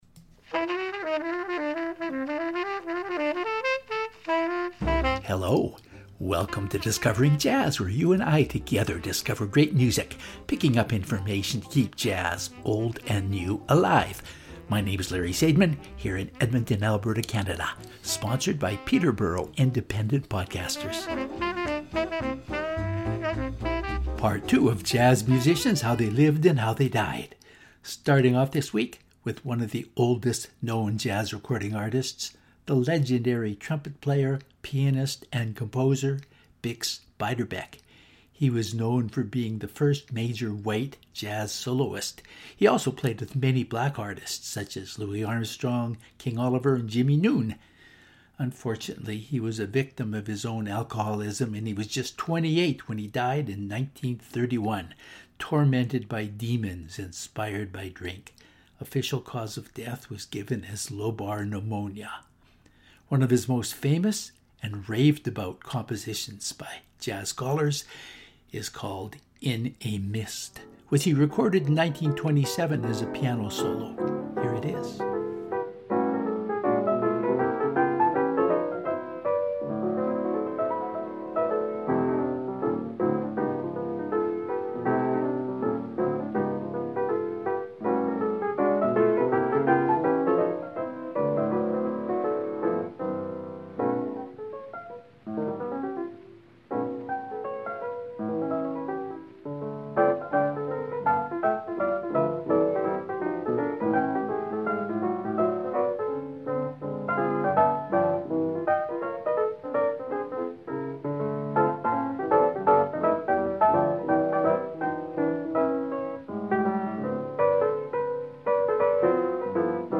Lots of old recordings today.
But they’re all by great jazz artists who we wish had been around a lot longer than they were.